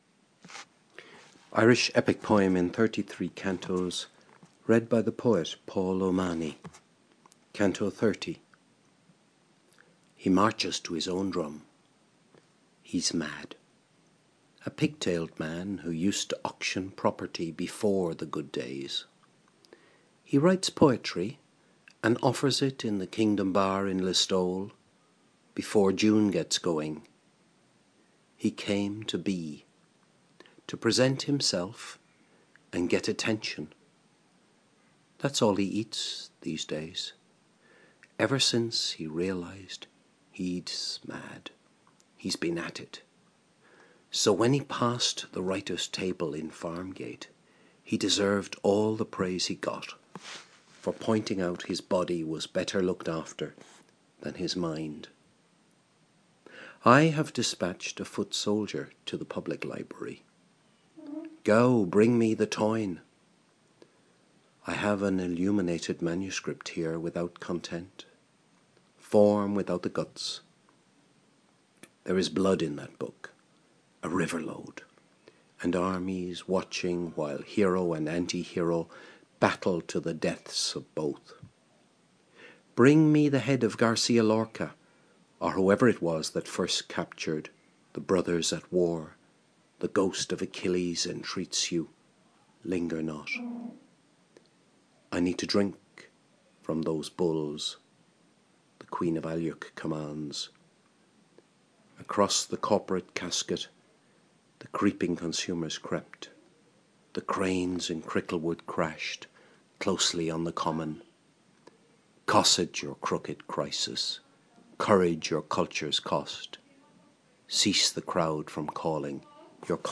Irish Epic Poem in 33 Cantos - Canto 30 - read by the poet